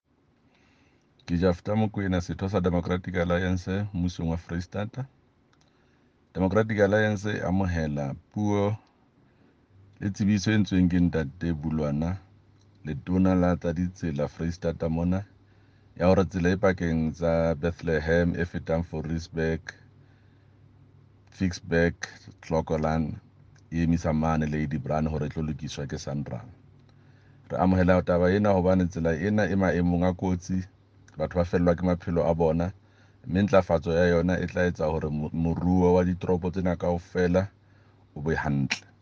Sotho soundbites by Jafta Mokoena MPL and
Sotho-soundbite.mp3